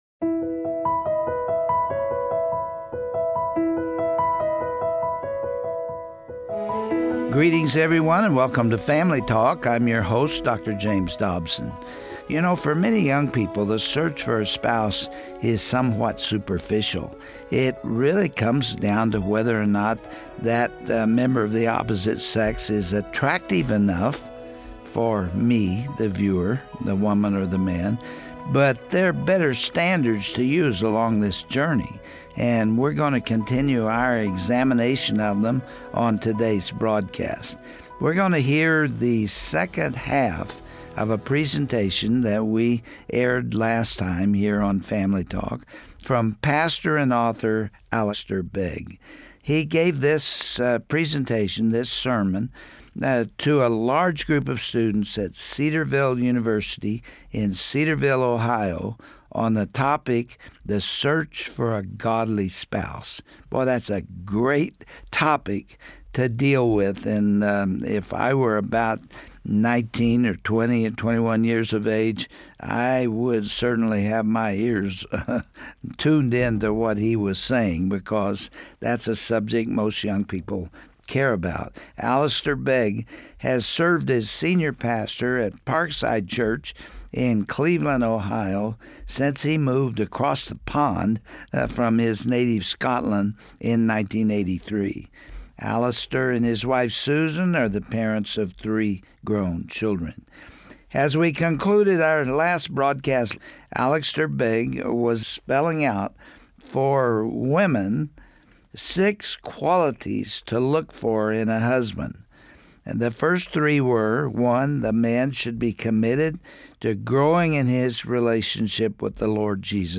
What advice does the Bible offer for those who are dating or pursuing marriage? Pastor Alistair Begg concludes his helpful message concerning the search for a godly spouse. He continues explaining why a persons authentic relationship with Jesus is the most significant attribute in a prospective mate.